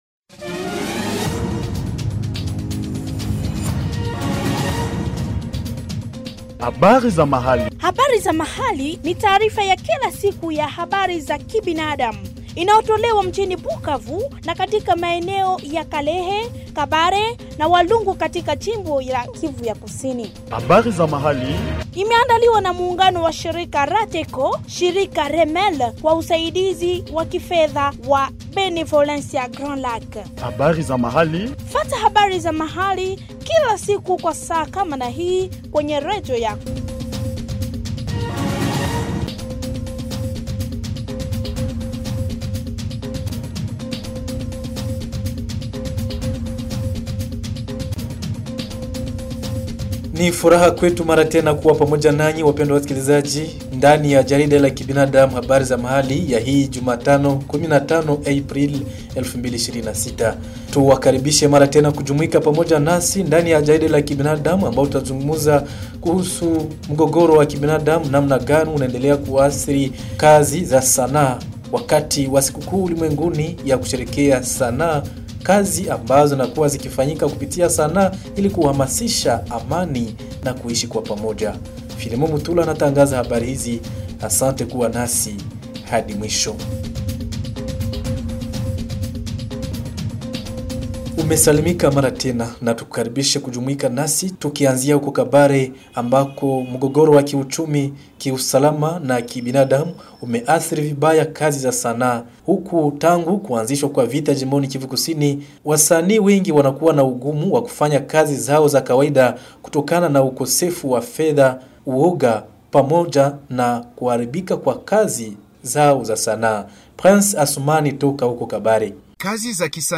Suivez ici le bulletin Habari za Mahali du 15 avril 2026 produit au Sud-Kivu